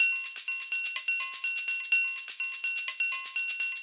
Live Percussion A 01.wav